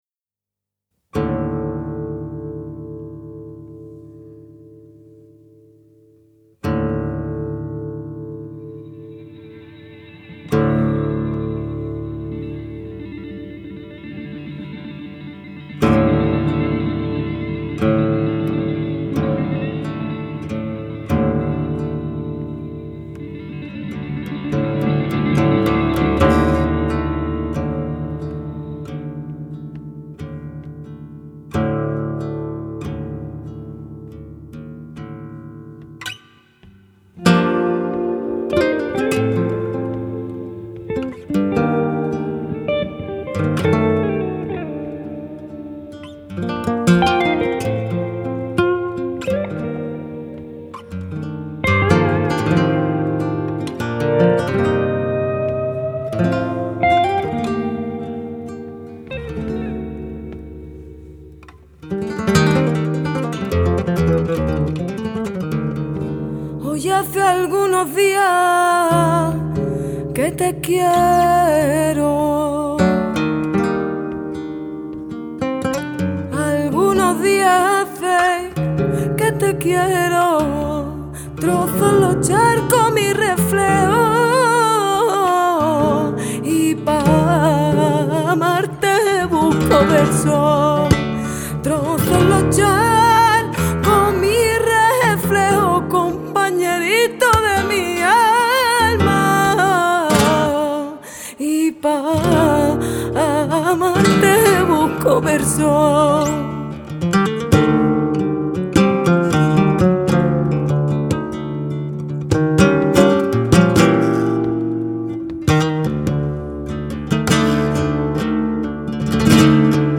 soleá
guitare électrique